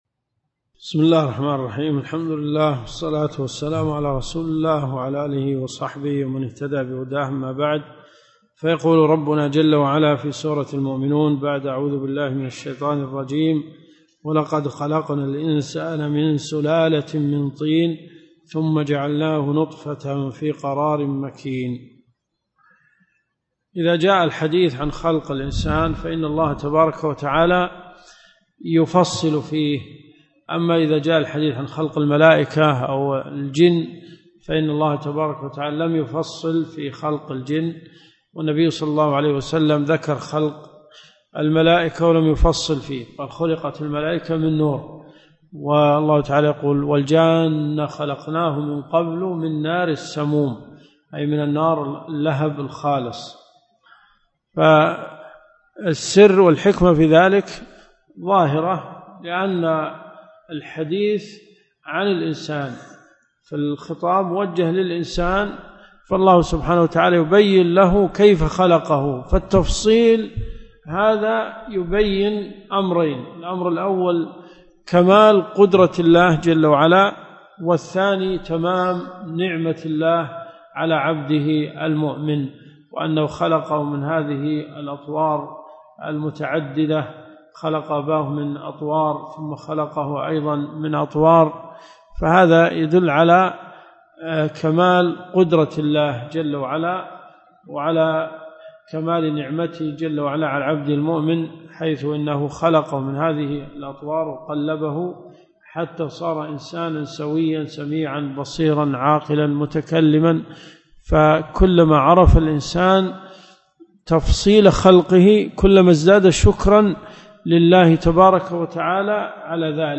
الرئيسية الدورات الشرعية [ قسم التفسير ] > تفسير سورة المؤمنون . 1430 .